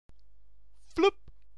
floep.wma